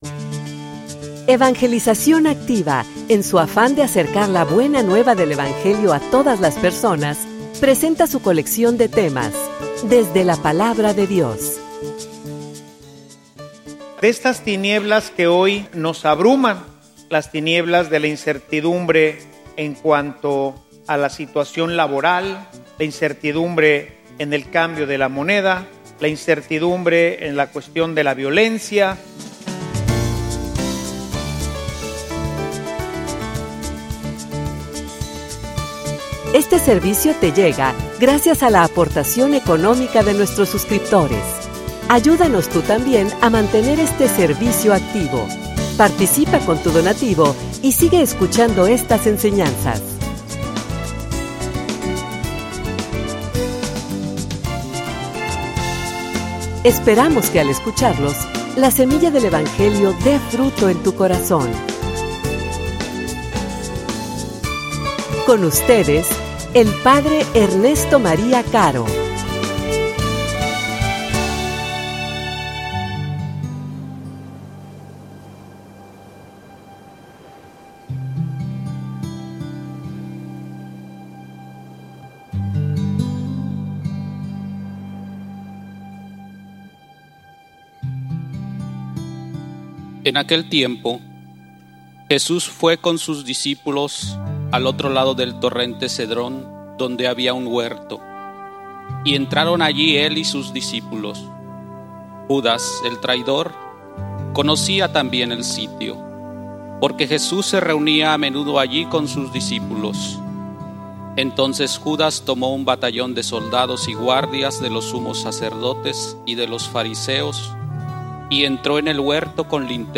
homilia_La_oscuridad_presagia_la_libertad.mp3